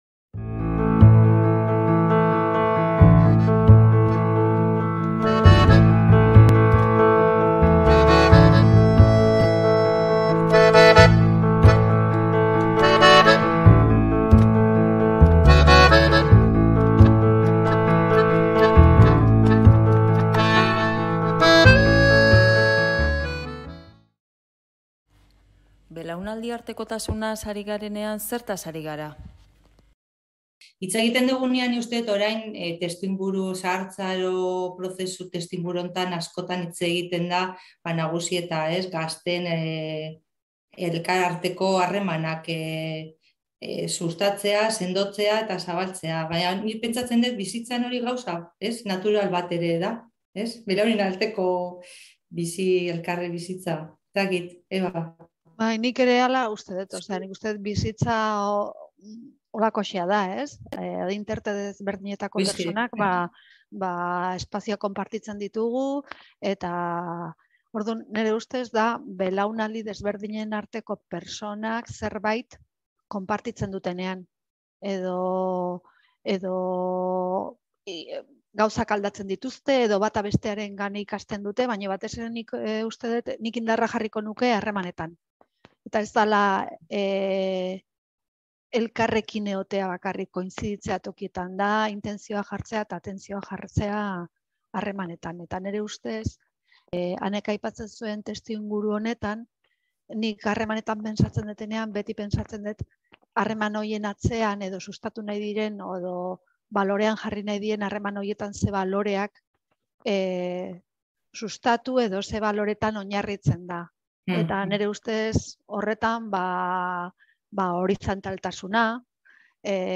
Elkarrizketan, biztanleen zahartzearen kudeaketa arduratsuaren erronka nagusiei buruz aritu dira, gure testuinguru sozial eta ekonomikoan.